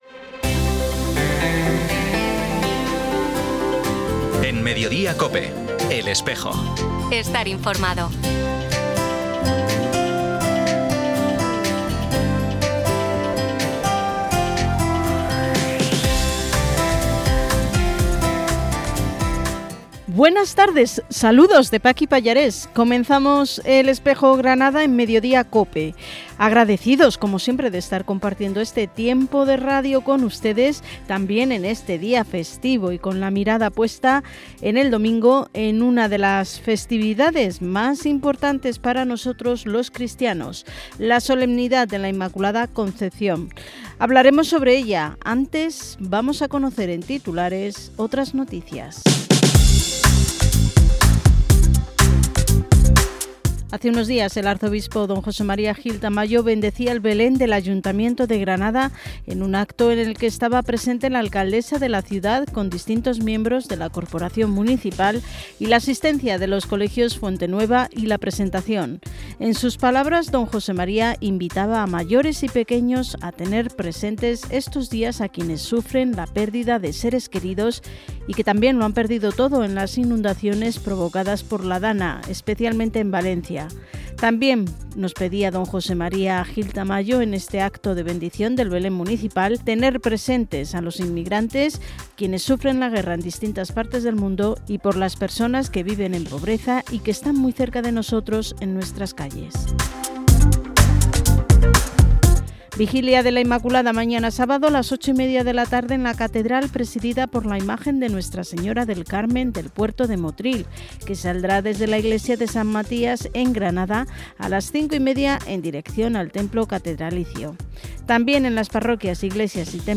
Programa emitido en COPE Granada y COPE Motril el 6 de diciembre de 2024.
Y escuchamos al Papa Francisco en su intención de oración para este mes de diciembre, dedicado al Jubileo 2025 que está a punto de iniciarse, sobre la esperanza cristiana.